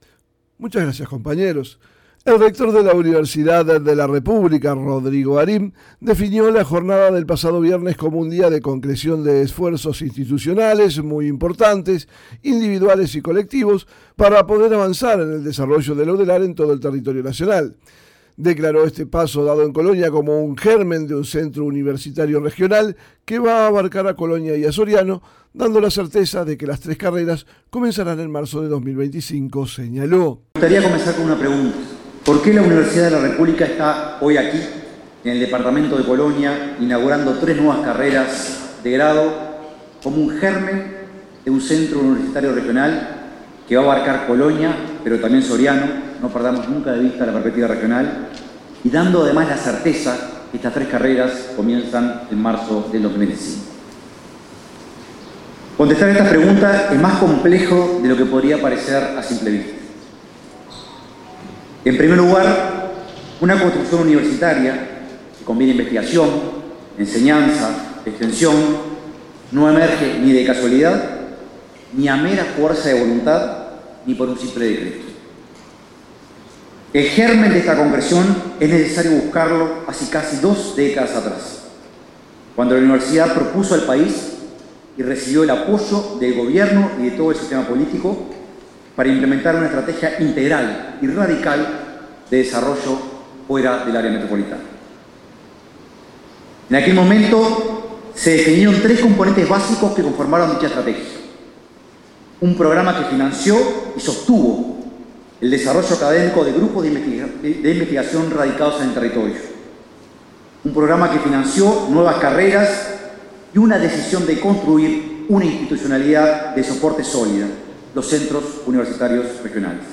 Informe